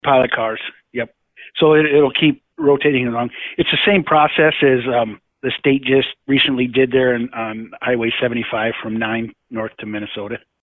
And, the asphalt overlay part of that is what’s going on now. He tells us the road remains open, but there will be delays.